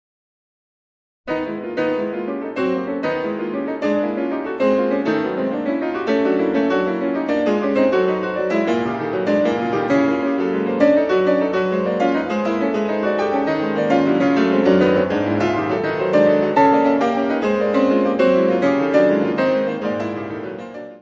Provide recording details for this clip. : stereo; 12 cm + folheto